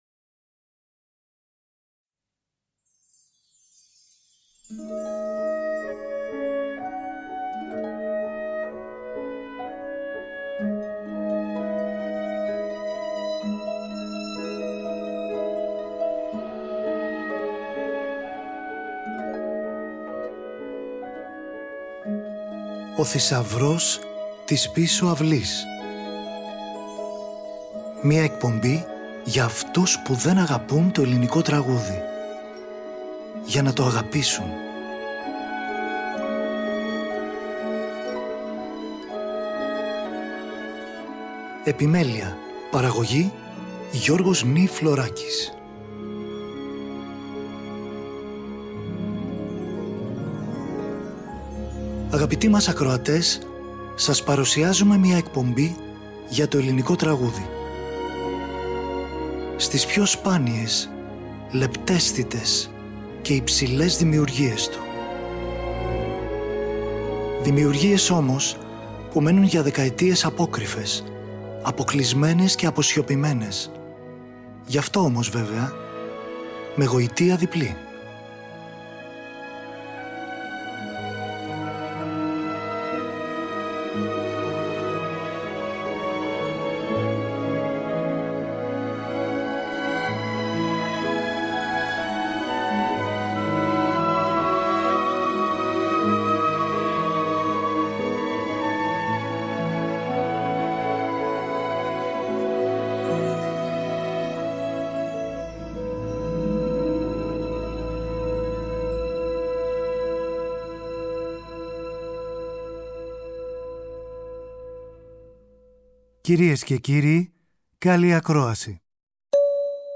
Για όσους δεν αγαπούν το ελληνικό τραγούδι.